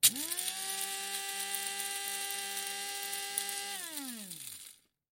Звуки кофемолки
Автоматическая кофемолка исправно работает